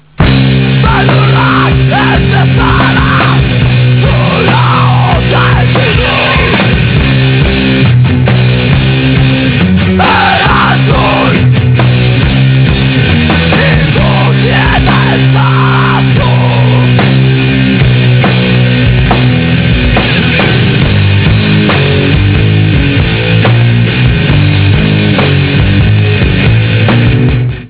8 KHz. Calidad mono.